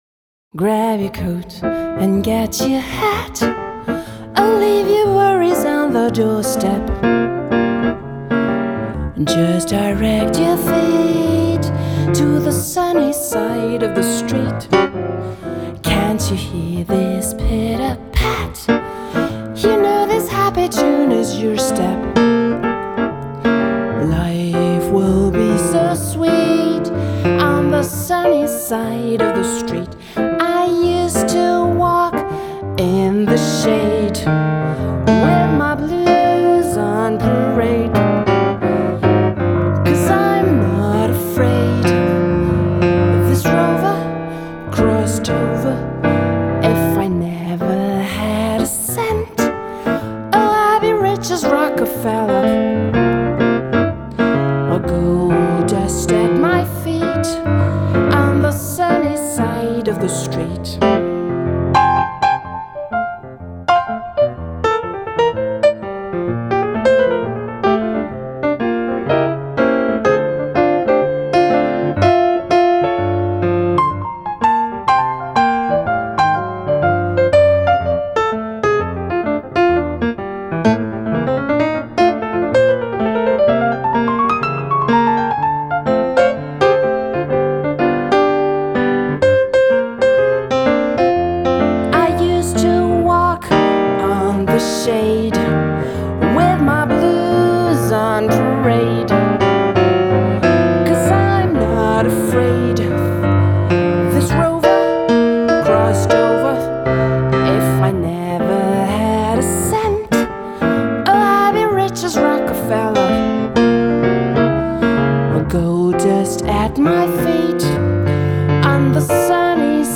Sängerin und Pianistin